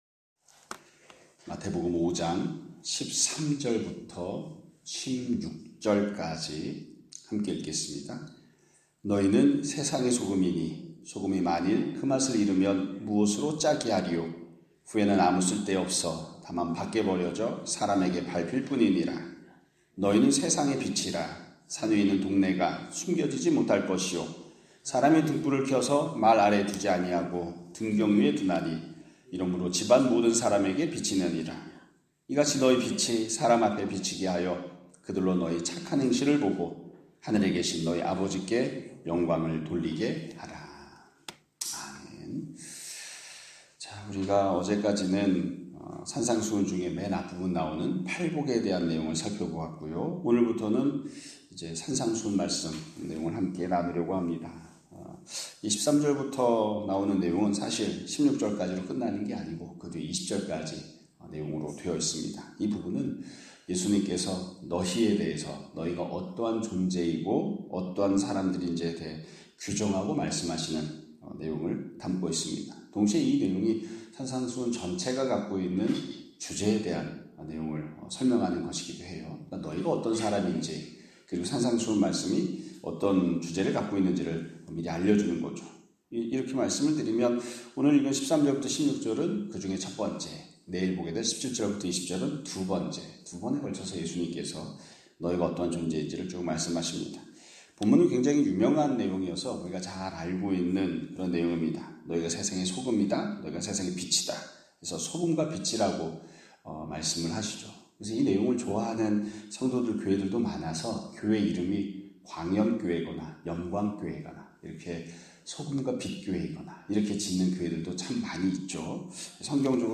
2025년 5월 20일(화요일) <아침예배> 설교입니다.